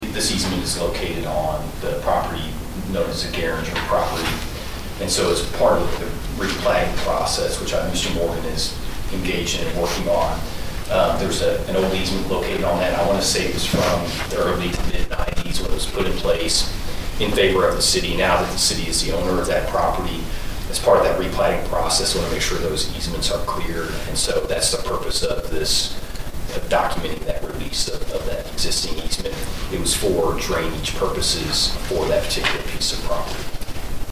City Attorney Josh Taylor explained what it’s about.